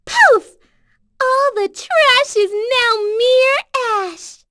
Pansirone-vox-get.wav